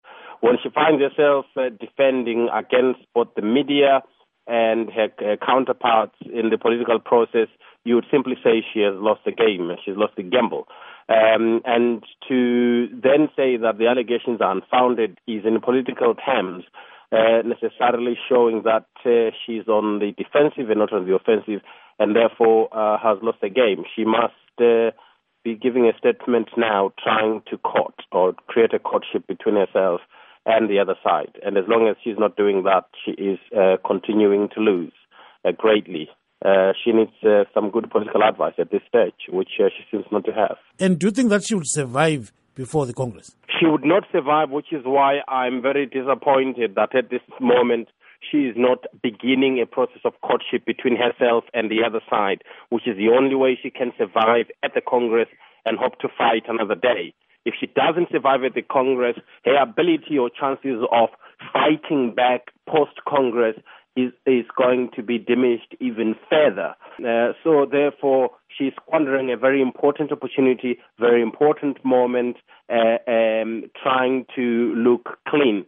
Political Analyst
Interview